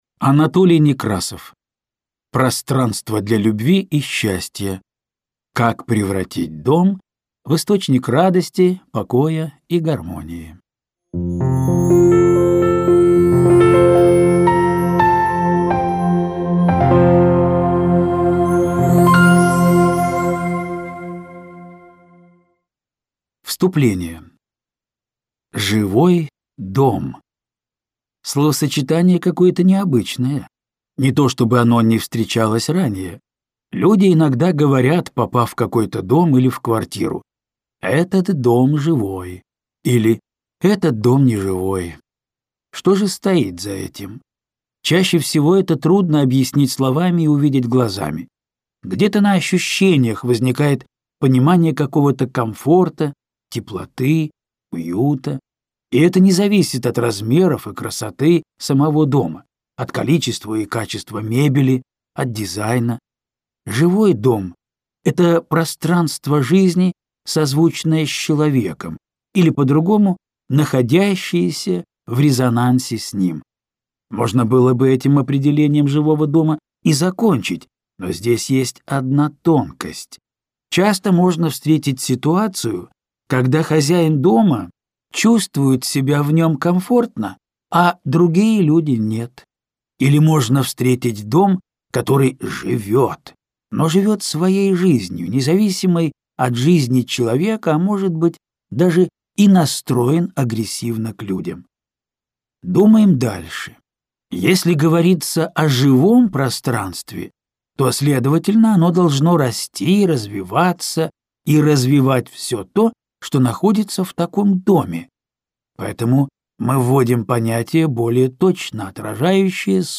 Аудиокнига Пространство для любви и счастья. Как превратить дом в источник радости, покоя и гармонии | Библиотека аудиокниг